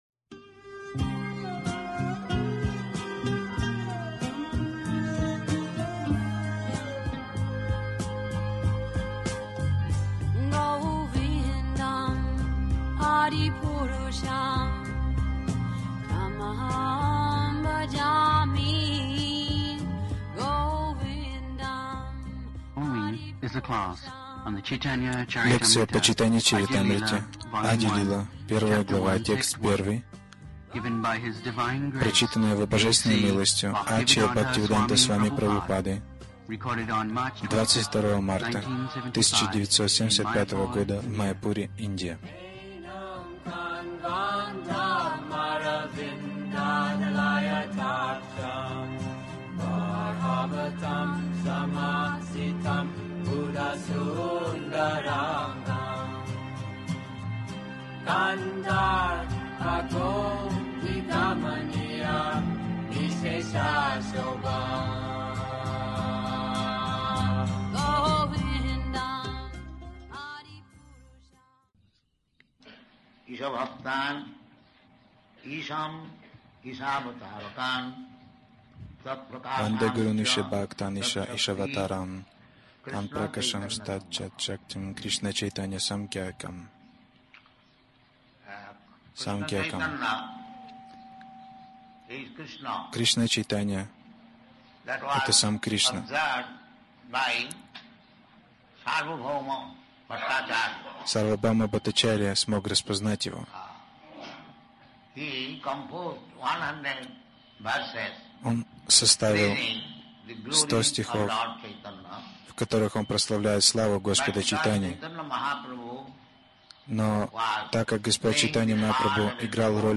Милость Прабхупады Аудиолекции и книги 25.03.1975 Чайтанья Чаритамрита | Маяпур ЧЧ Ади-лила 01.001 Загрузка...